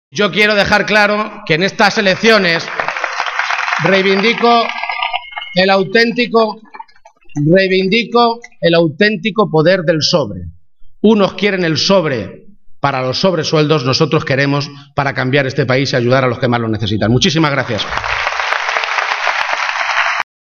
En ese acto público, con el Teatro Victoria abarrotado, Page ha dado toda una serie de argumentos por los que asegurar que una victoria del PSOE sobre el PP el domingo es importante.